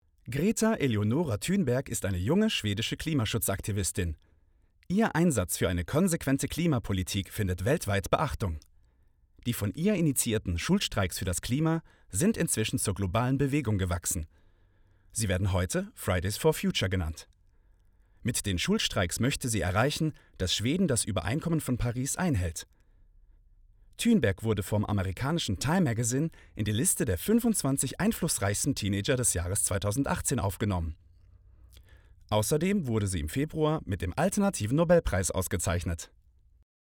Image- und Erklärfilm
freundlich, informativ
Demo-Doku_GretaThunberg.mp3